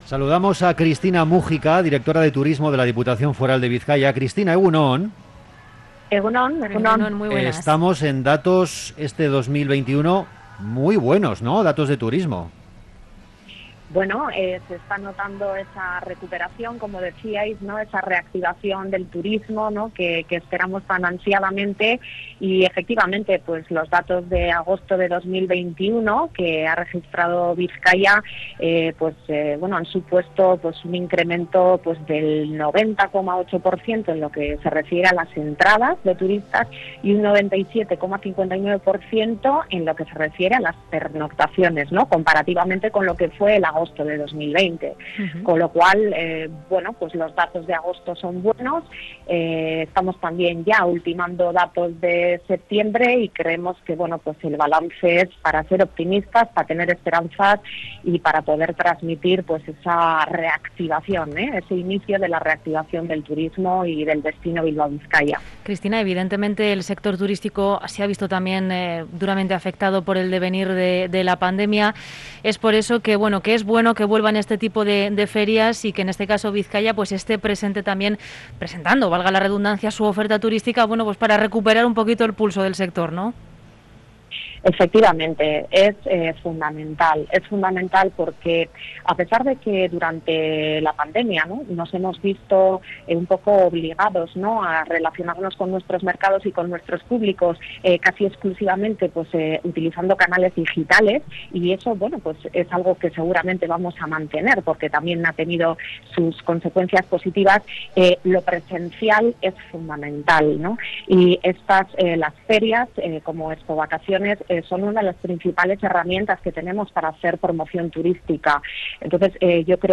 Onda Vasca se traslada al BEC de Barakaldo para un programa especial sobre Expovacaciones. La feria de turismo vuelve en formato presencial con más de 160 expositores. Cristina Múgica, directora de Turismo de la Diputación Foral de Bizkaia, nos ha explicado que es la primera feria presencial a la que acuden con stand propio para transmitir que estamos en esa senda de la recuperación, que Bilbao-Bizkaia se reactiva y que es un destino seguro.